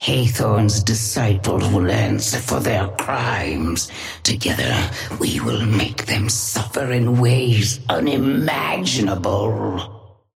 Sapphire Flame voice line - Hathorne's disciples will answer for their crimes.
Patron_female_ally_orion_start_07.mp3